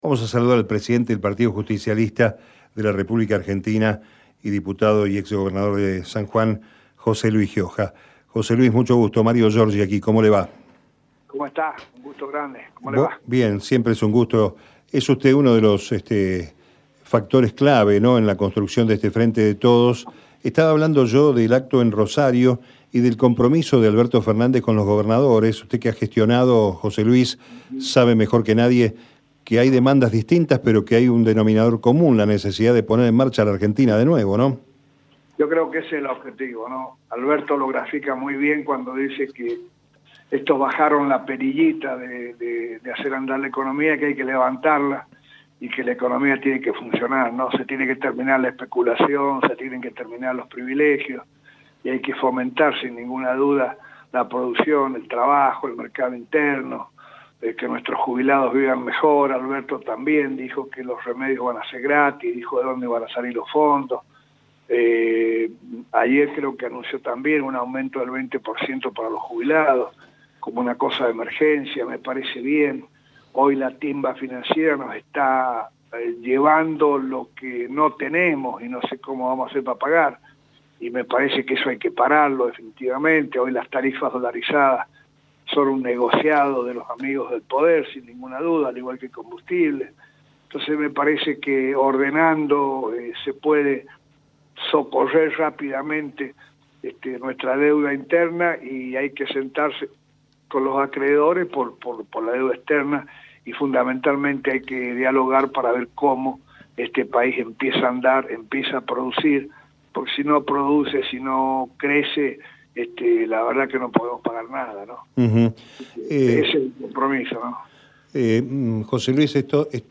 Hoy en Meridiano Electoral entrevistamos a: José Luis Gioja